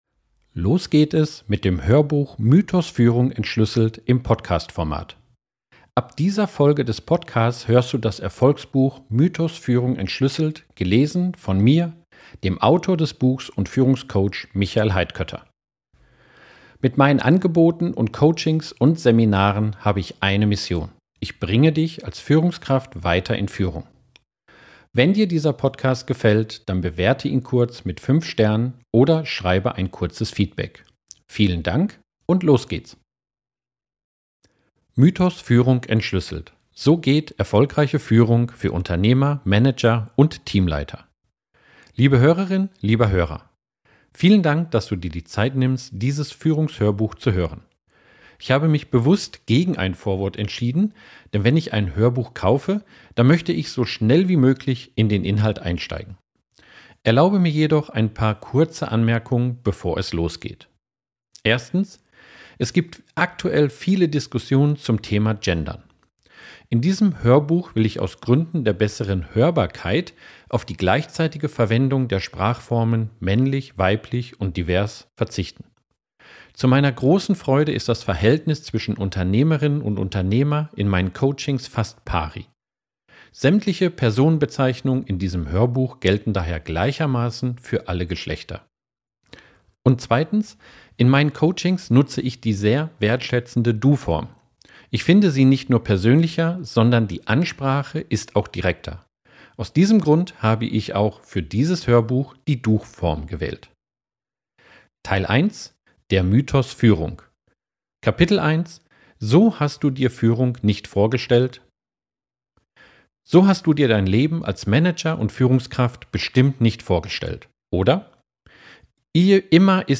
Du erhältst das vollständige Hörbuch gratis und ganz bequem im Podcast-Format zum Hören im Auto, auf Reisen, beim Sport oder im Café.